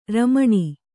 ♪ ramaṇi